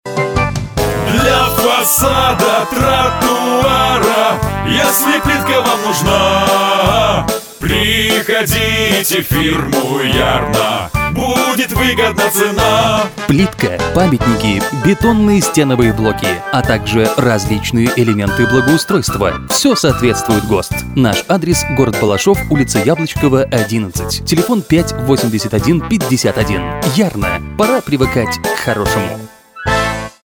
Вокальный аудиоролик